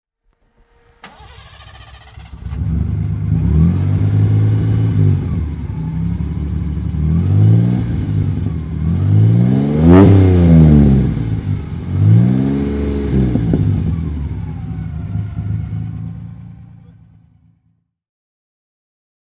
Gilbern Invader Mk III (1973) - Starten und Leerlauf
Gilbern_Invader_1973.mp3